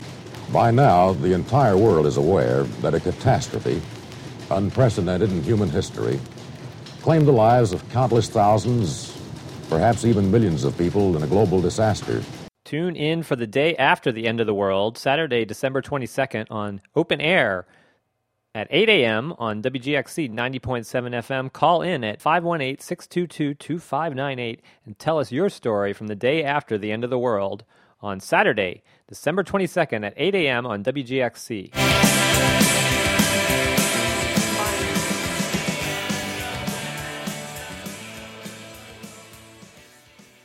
An Official PSA for "Open Air" program on WGXC Sat., Dec. 22 at 8 a.m. asking audiences to call-in with stories from the day after the end of the world.